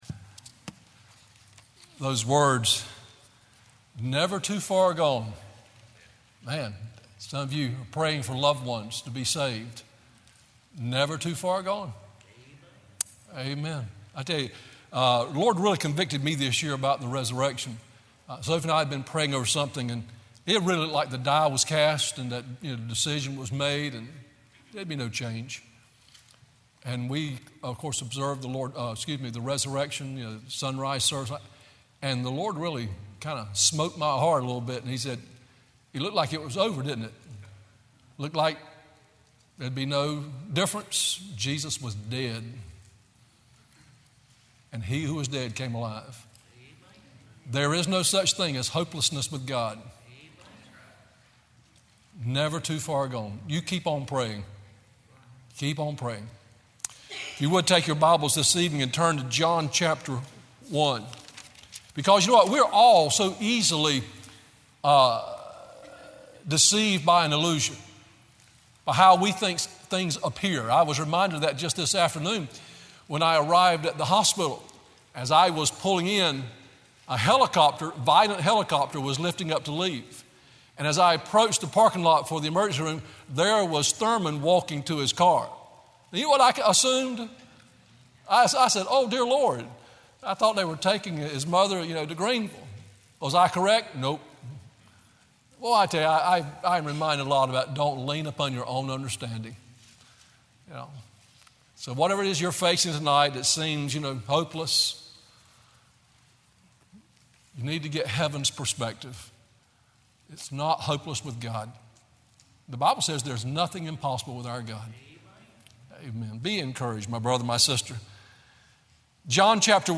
Sermon Audios/Videos - Tar Landing Baptist Church